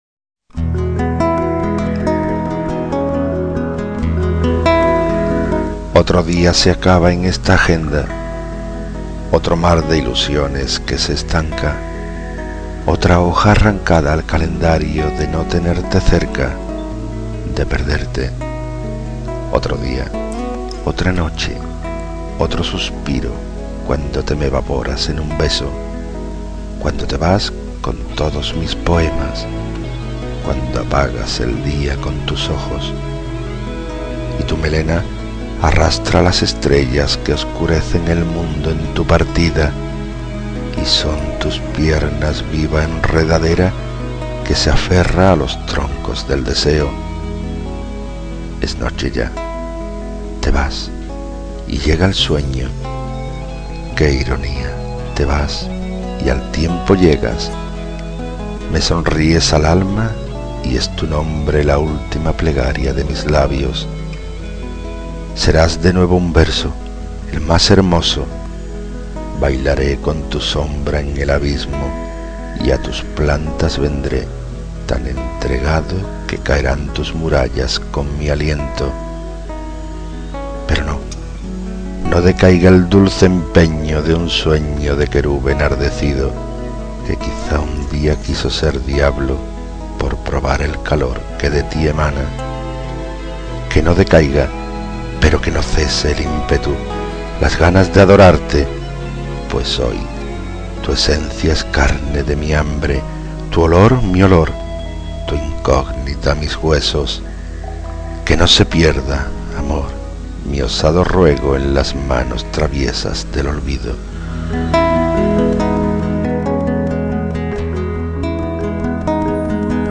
Inicio Multimedia Audiopoemas Un día menos.